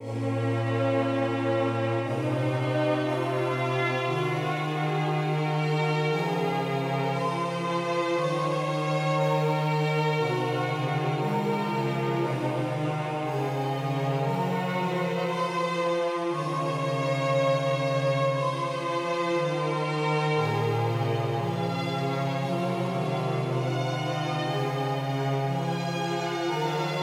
Žánr : elektronická hudba
Synth Echo + Warm